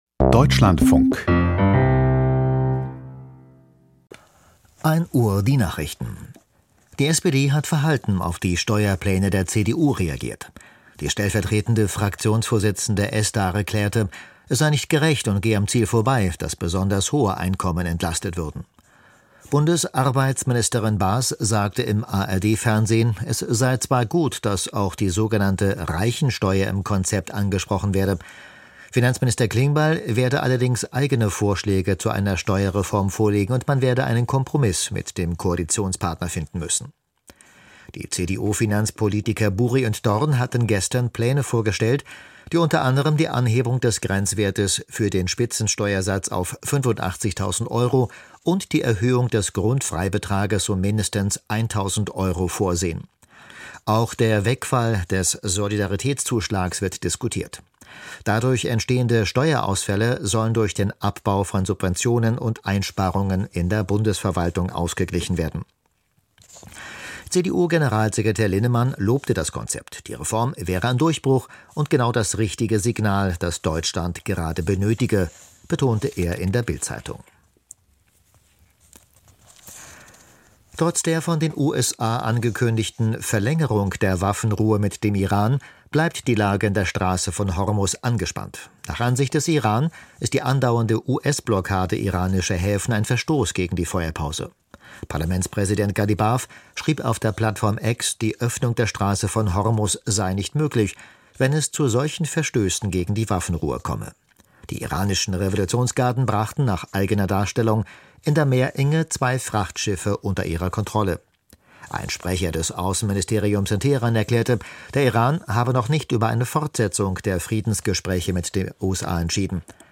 Die Nachrichten vom 23.04.2026, 01:00 Uhr
Aus der Deutschlandfunk-Nachrichtenredaktion.